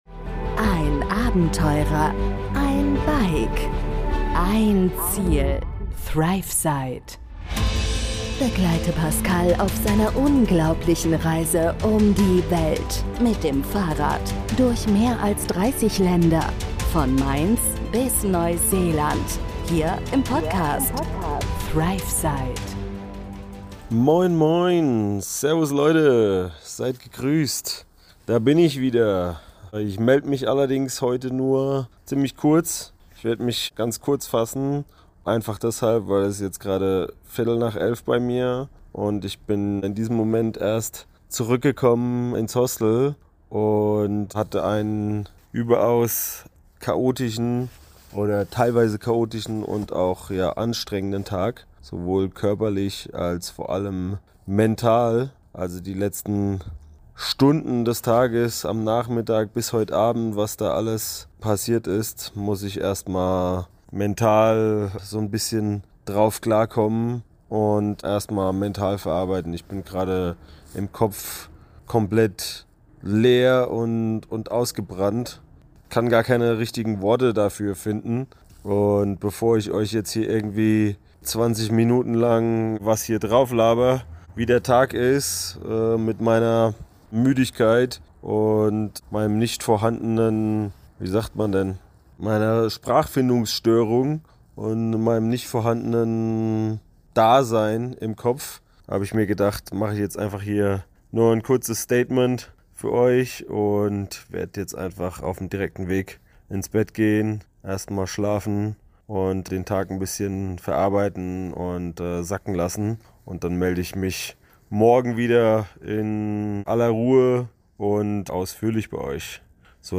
echtes Live-Update direkt aus dem Hostel!